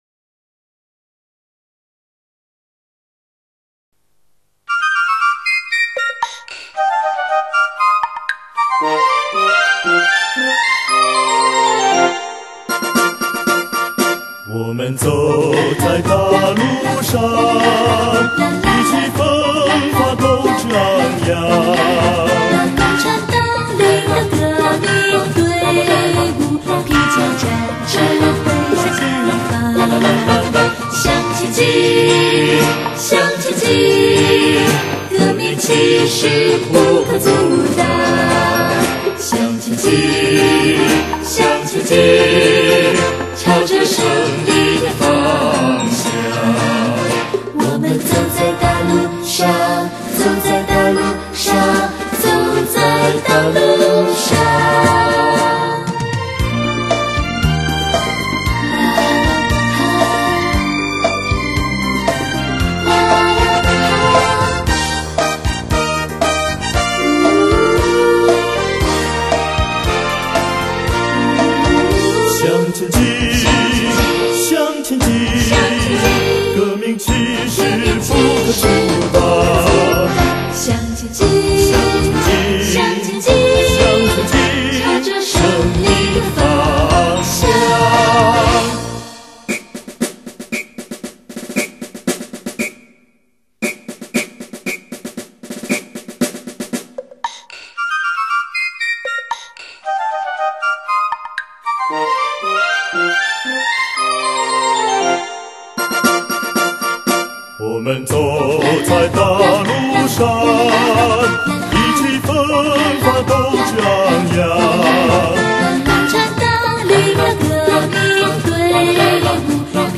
男聲
女聲